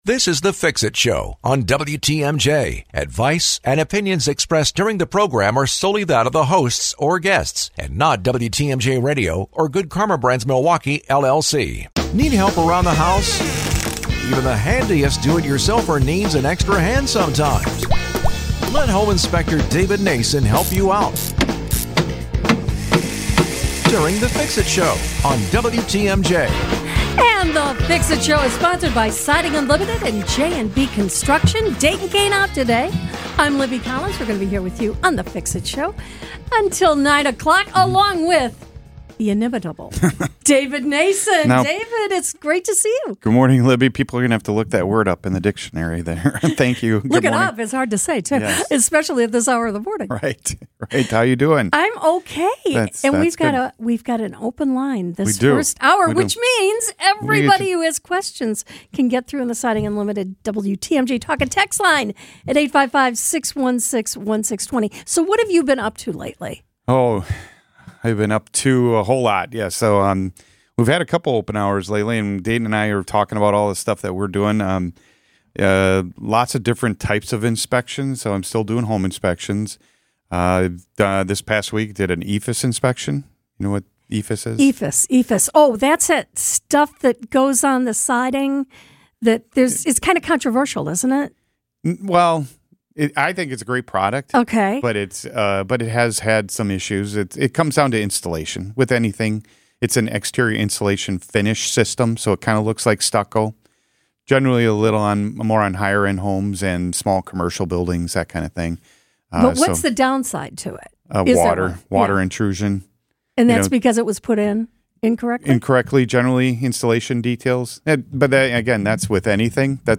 discuss home improvement projects and talk with special guests every Saturday morning